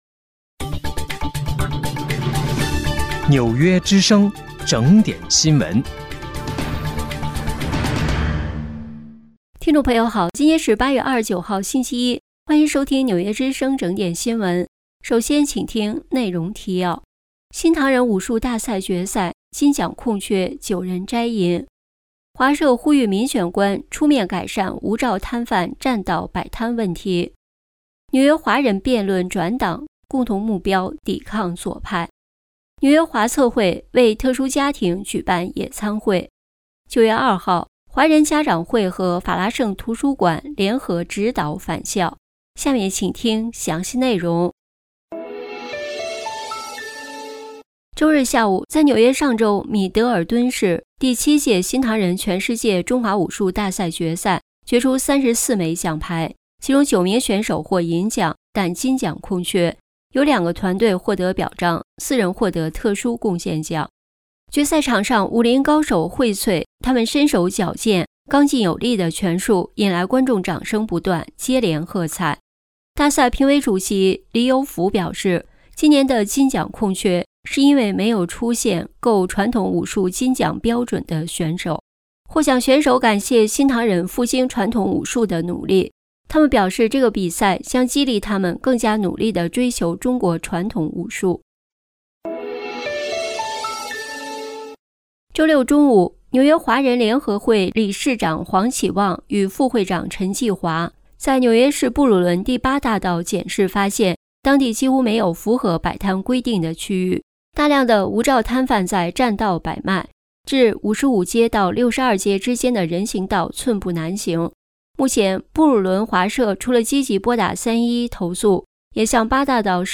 8月29日（星期一）纽约整点新闻